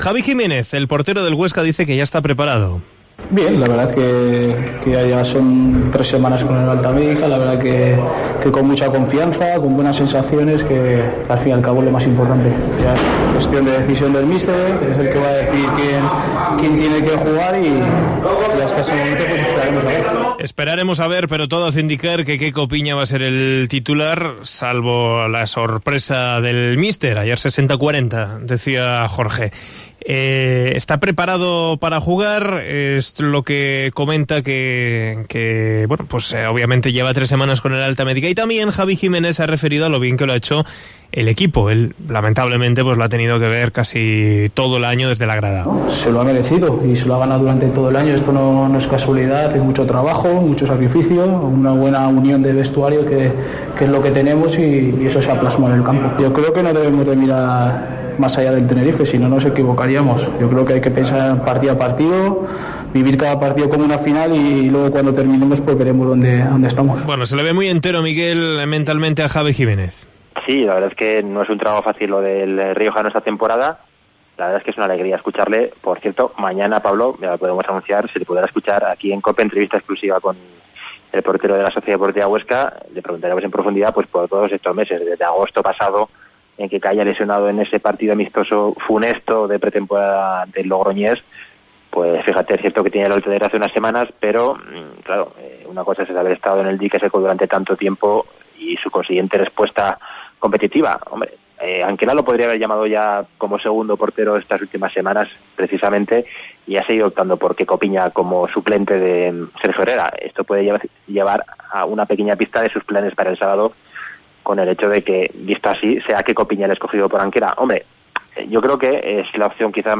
en zona mixta